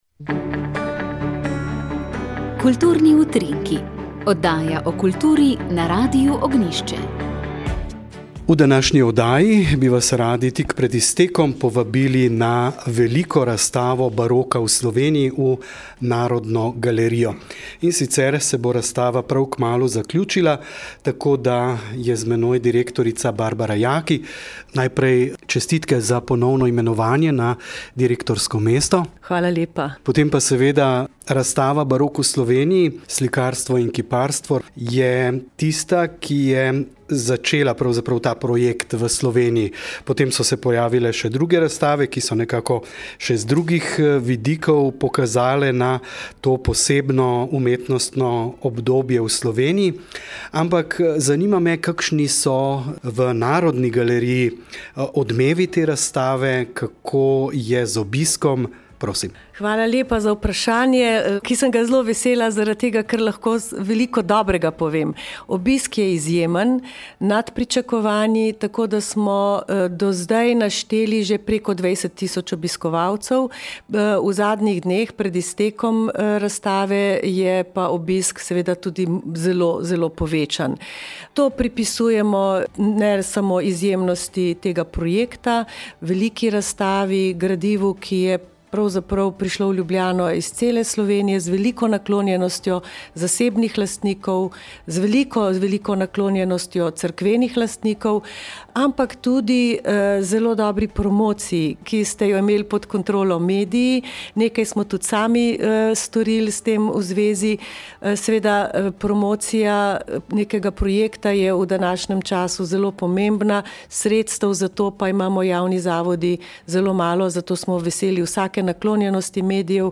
Sveta maša
Sv. maša iz stolnice sv. Janeza Krstnika v Mariboru